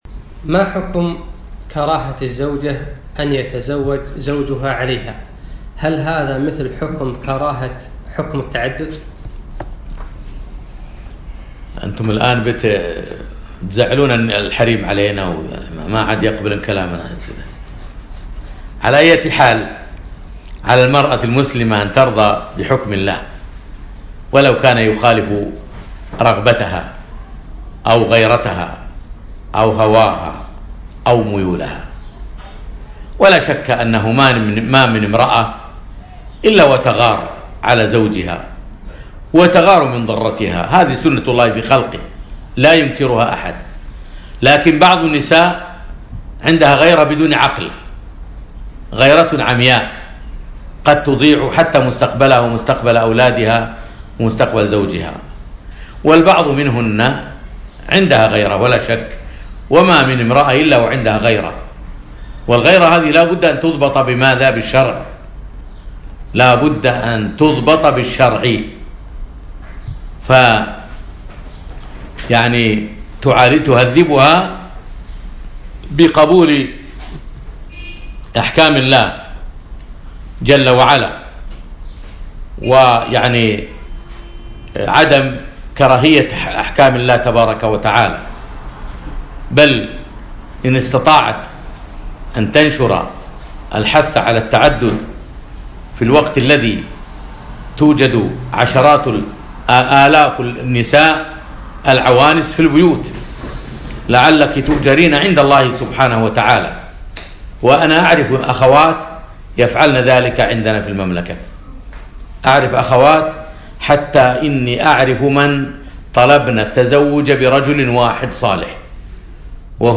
يوم الأحد 5 رجب 1438 الموافق 2 4 2017 في أترجة الفحيحيل نسائي صباحي